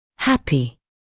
[‘hæpɪ][‘хэпи]счастливый, доволный